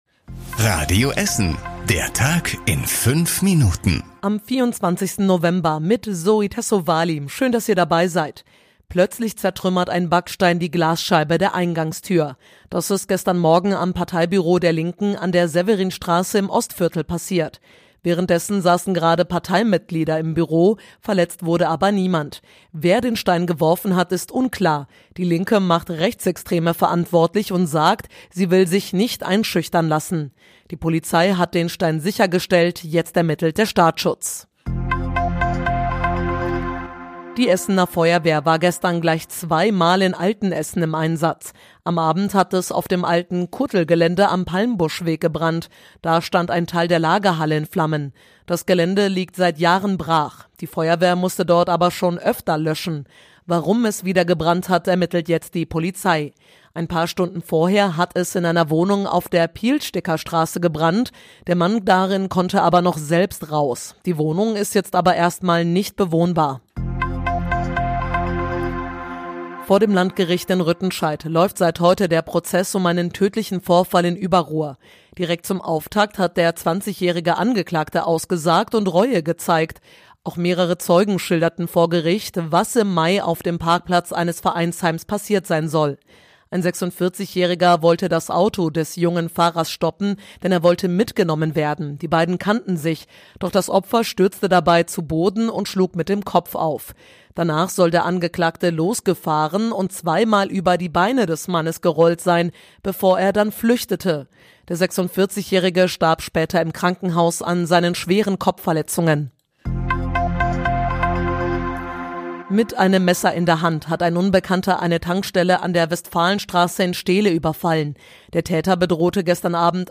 Die wichtigsten Nachrichten des Tages in der Zusammenfassung
Nachrichten